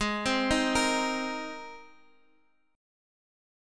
G#add9 chord